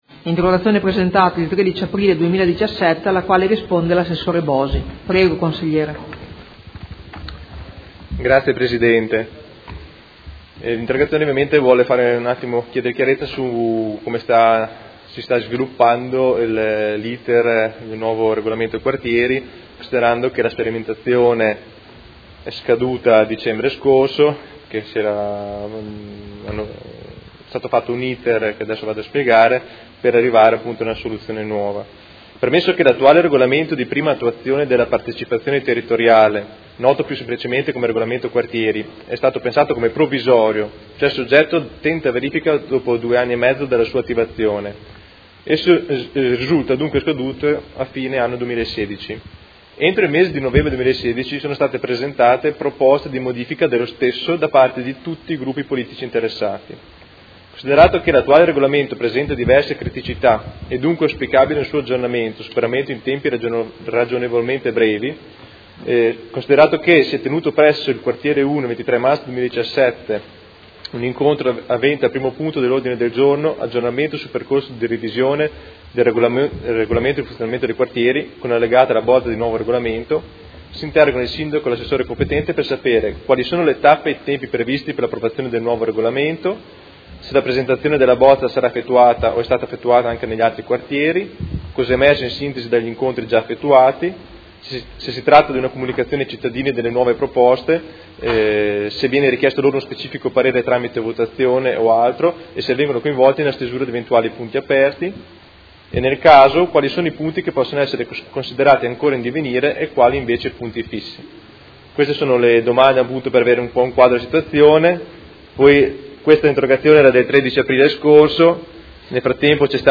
Seduta del 11/05/2017 Interrogazione del Gruppo Movimento cinque Stelle avente per oggetto: Percorso nuovo Regolamento Quartieri